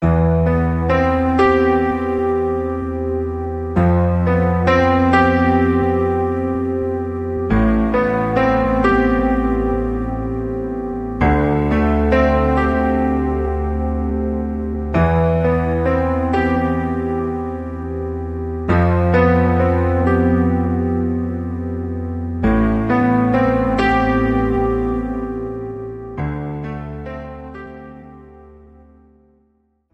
This is an instrumental backing track cover.
• Key – C
• Without Backing Vocals
• No Fade